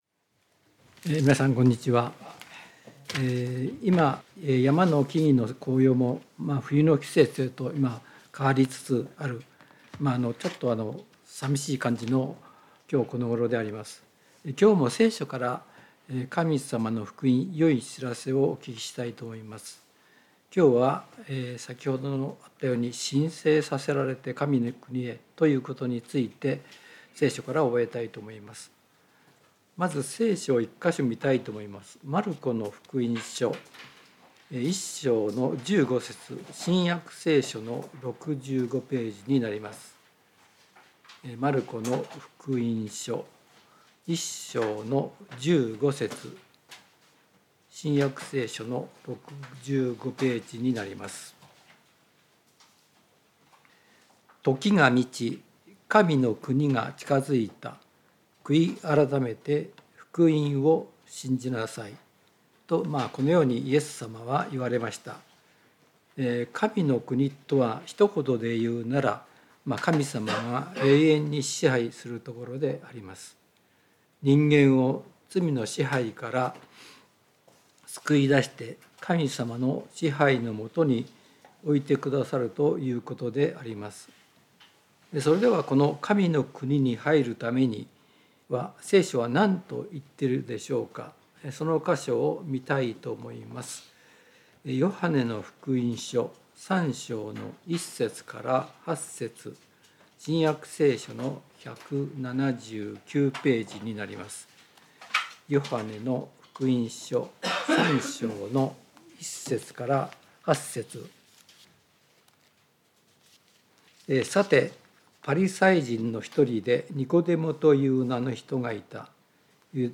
聖書メッセージ No.296